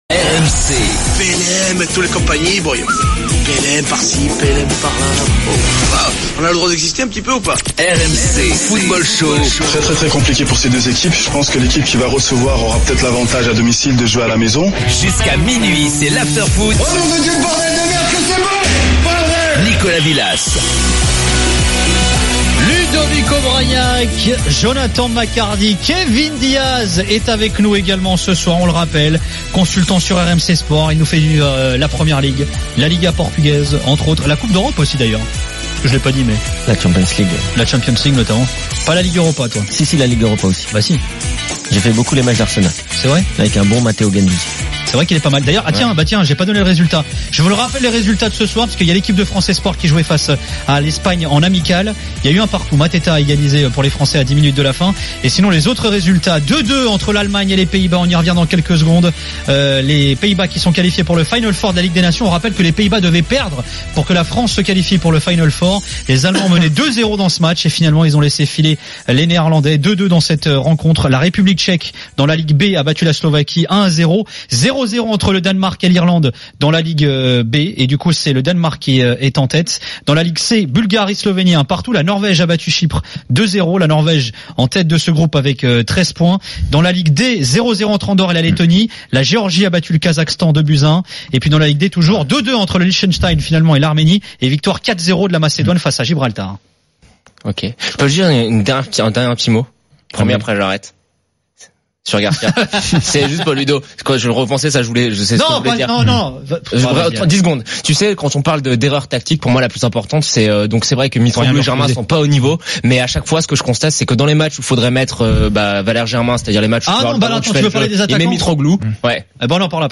le micro de RMC est à vous !